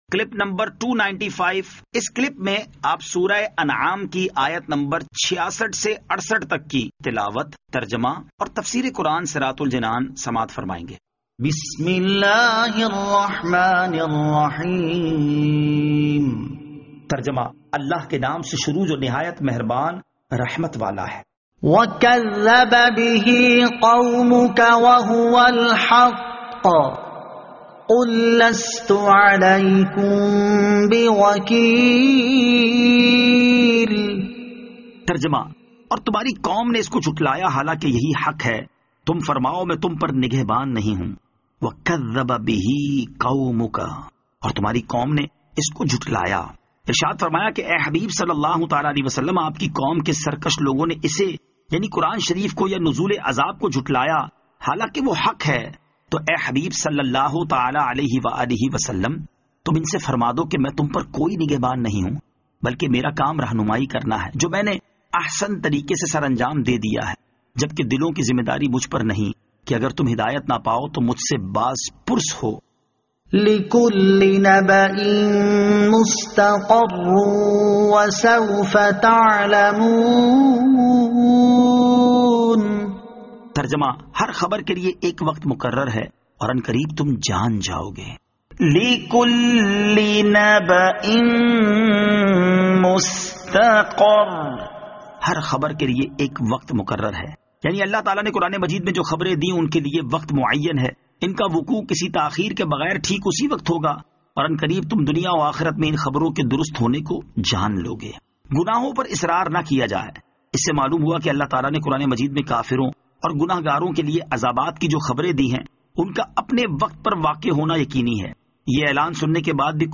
Surah Al-Anaam Ayat 66 To 68 Tilawat , Tarjama , Tafseer